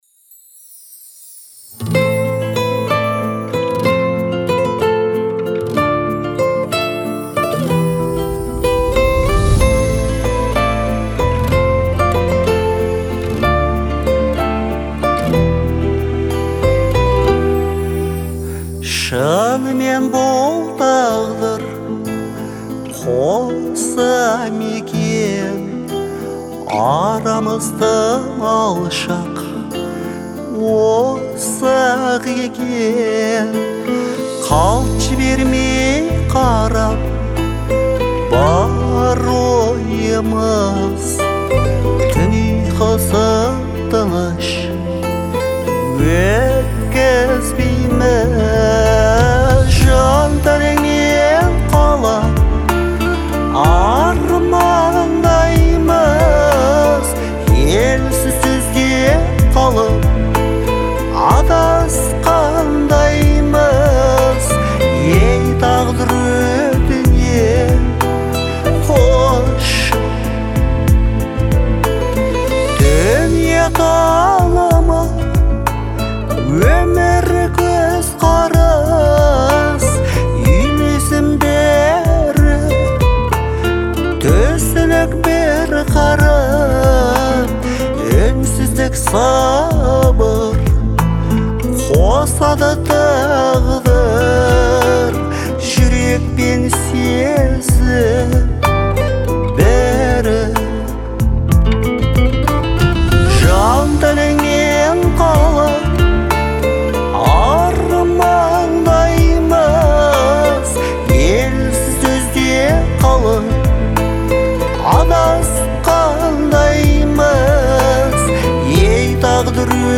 поп
обладает меланхоличным, но вдохновляющим настроением